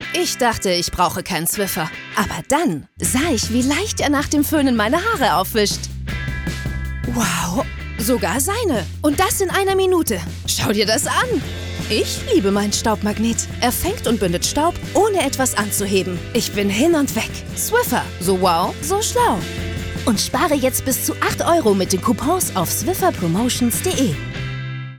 markant, sehr variabel, hell, fein, zart
Jung (18-30)
Commercial (Werbung)